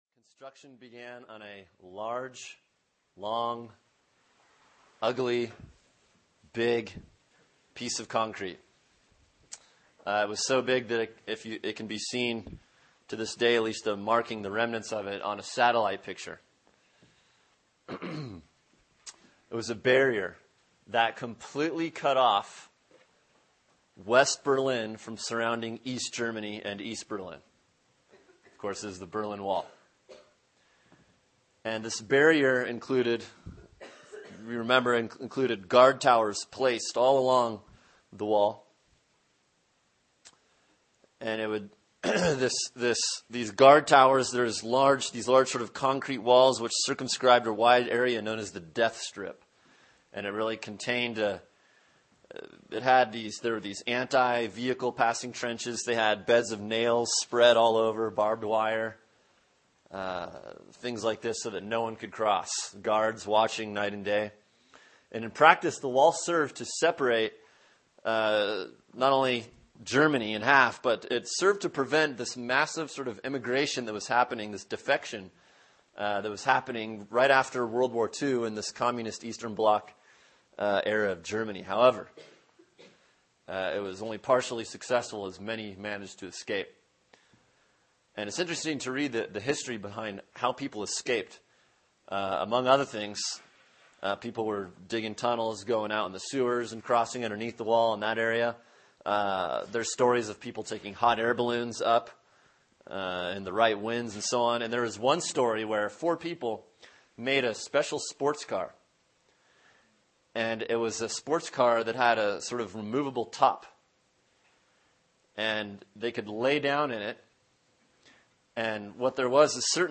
Sermon: Mark 15:38 “The Story of the Veil” | Cornerstone Church - Jackson Hole